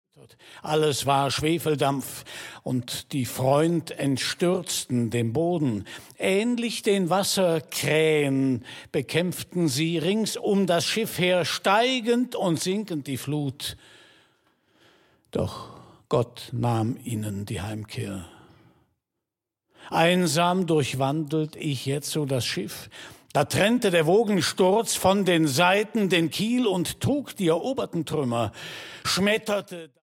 narration recorded 2010 at Domicil, Dortmund